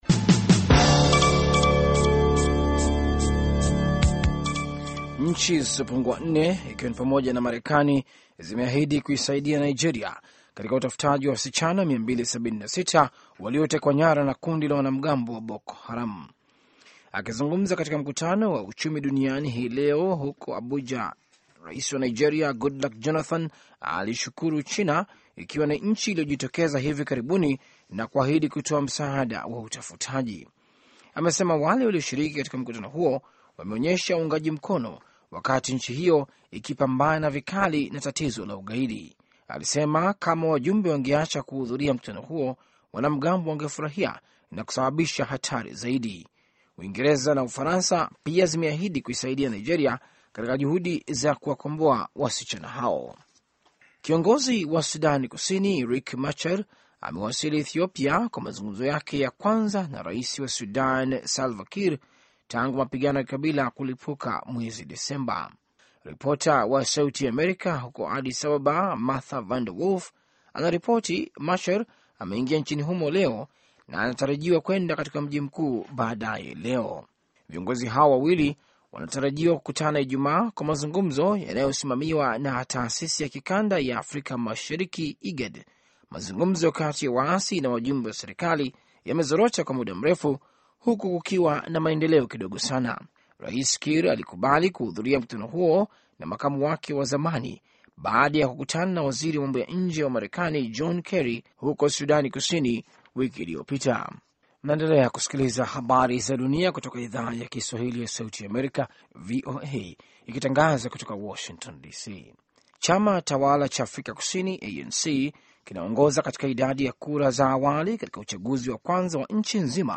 Taarifa ya Habari VOA Swahili - 6:10